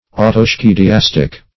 ([add]`t[-o]*sk[=e]`d[i^]*[a^]s"t[i^]k)
autoschediastic.mp3